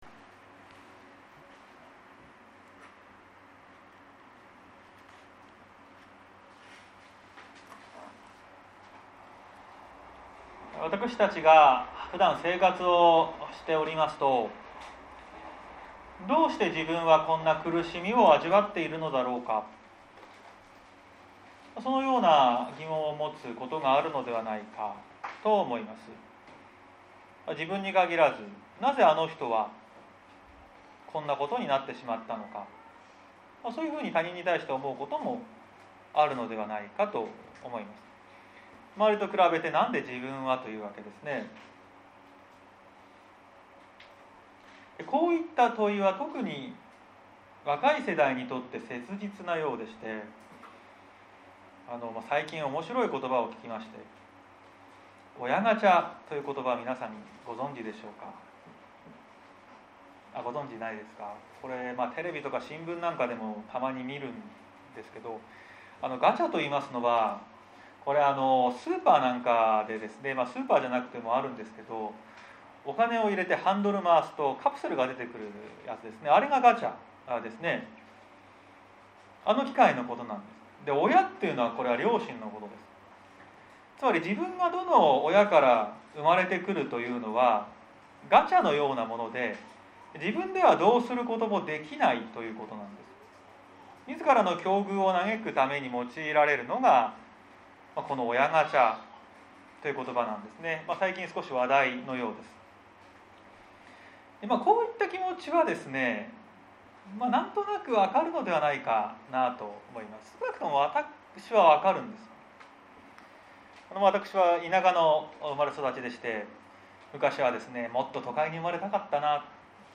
2021年09月26日朝の礼拝「因果応報から解き放たれ」綱島教会
説教アーカイブ。